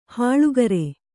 ♪ hāḷugare